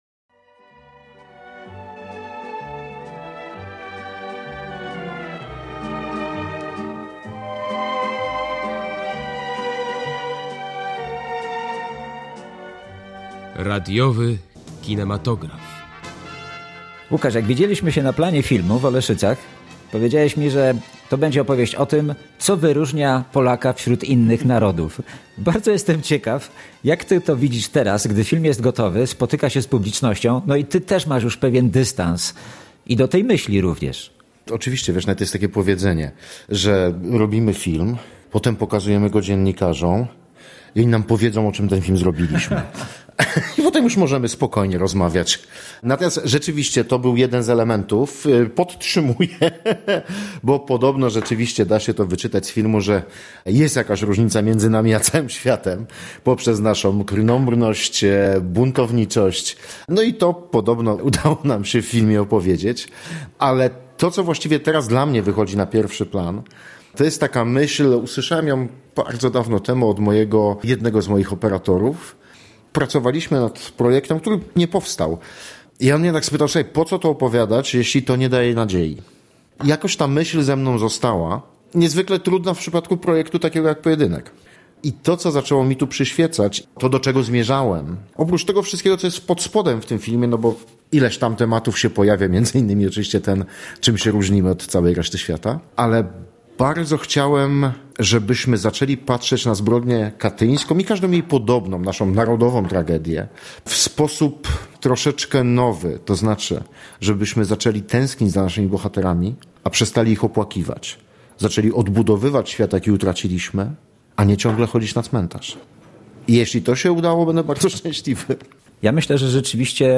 Z Łukaszem Palkowskim rozmawia